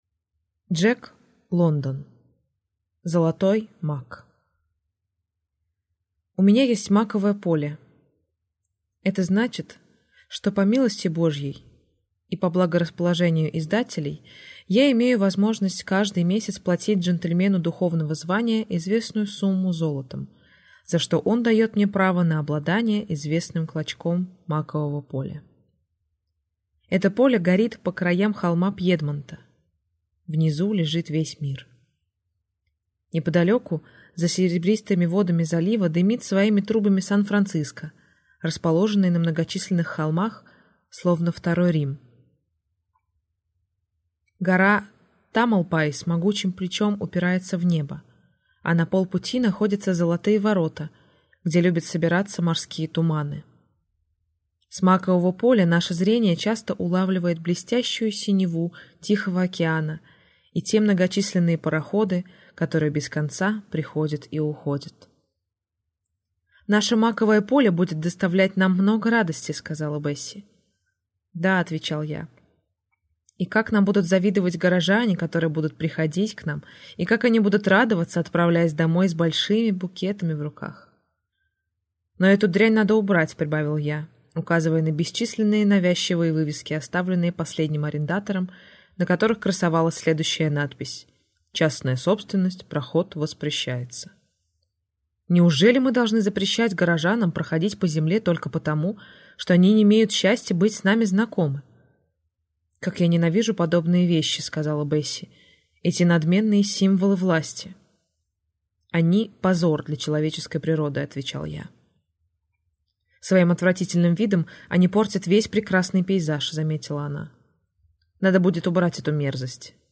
Аудиокнига Золотой мак | Библиотека аудиокниг